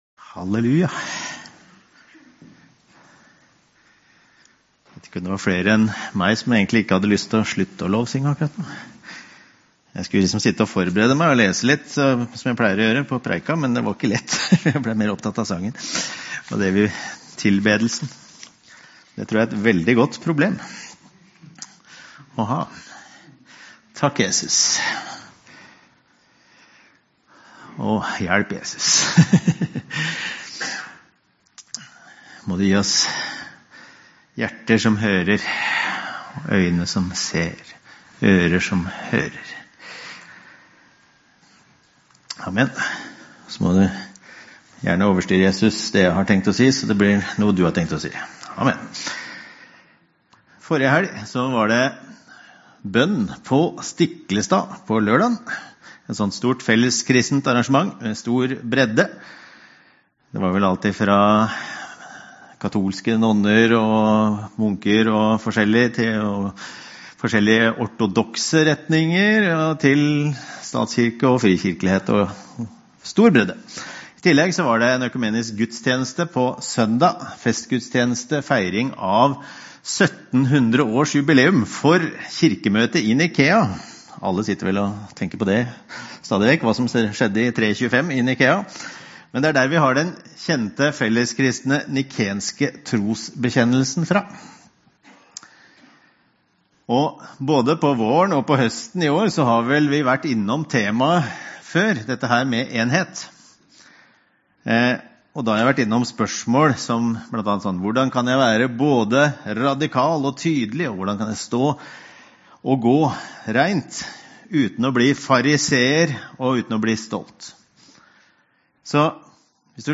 Tid: 26. oktober 2025 kl. 11 Stad: No12, Håkon den godes g. 12, Levanger